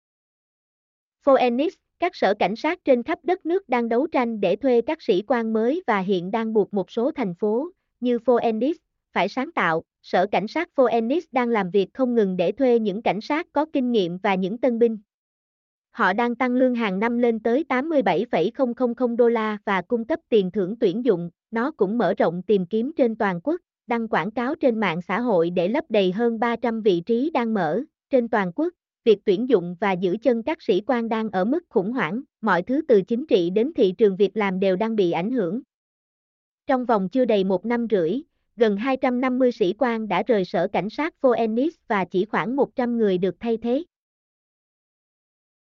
mp3-output-ttsfreedotcom-6.mp3